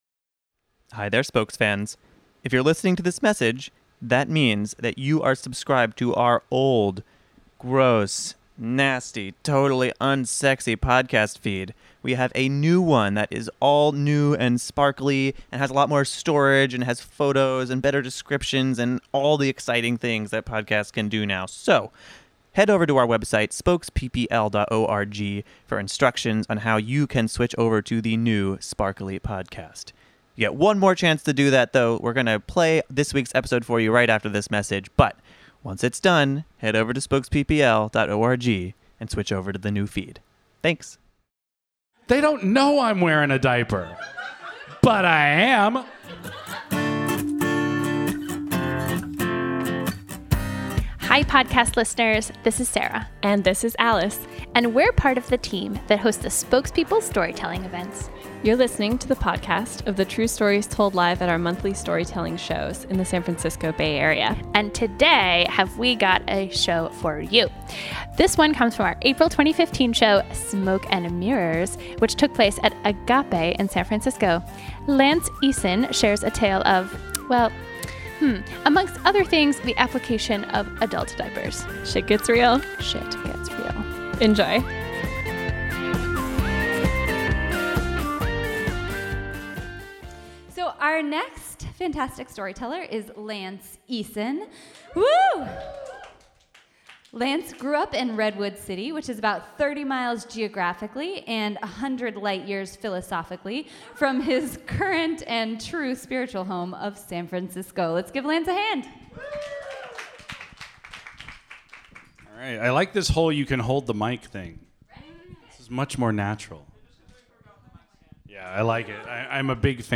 A fortuitous concert and an inconvenient illness led a man and some diapers to live nappily ever after. This story was told live at our April 2015 show, "Smoke & Mirrors."